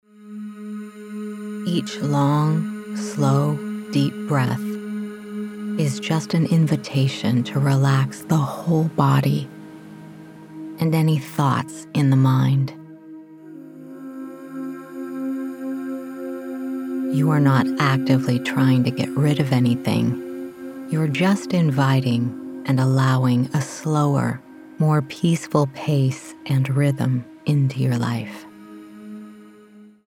Sample Daily Breathing Meditation.mp3